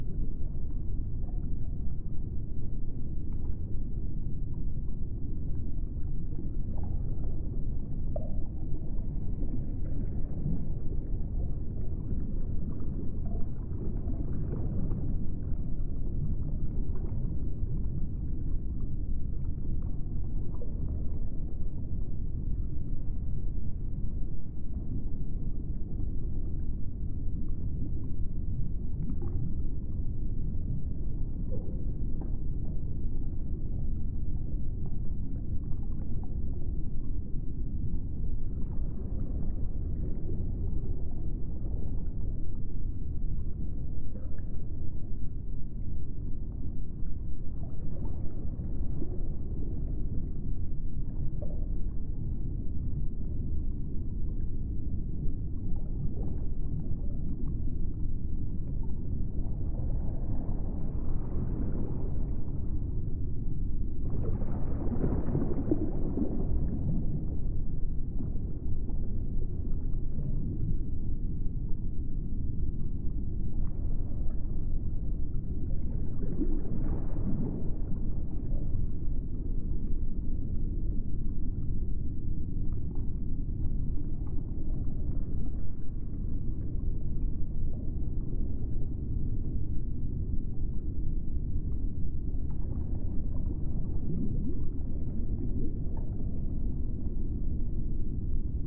underwater.wav